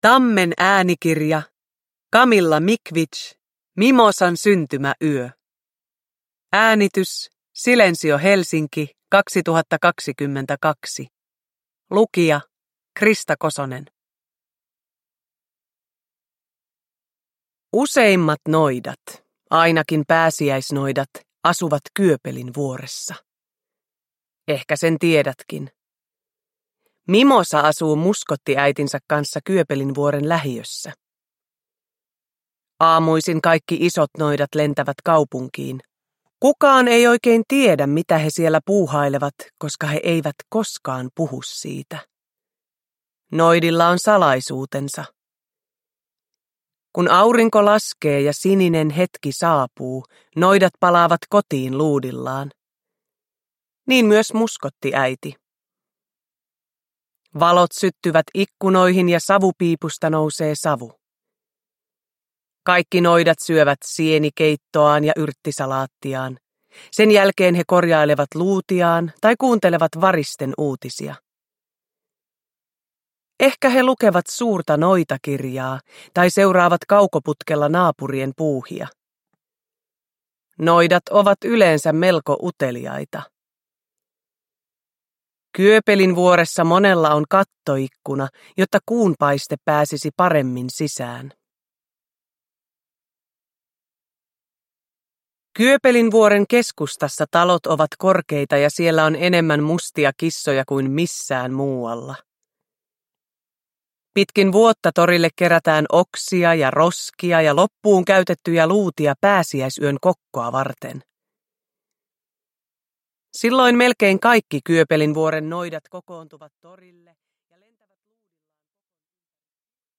Mimosan syntymäyö – Ljudbok – Laddas ner